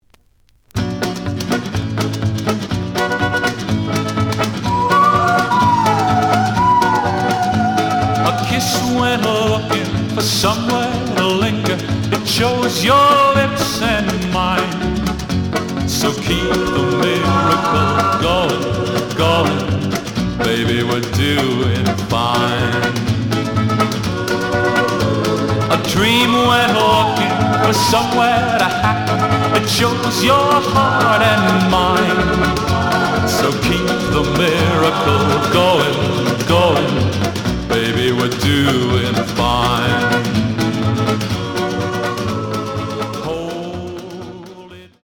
The audio sample is recorded from the actual item.
●Genre: Rock / Pop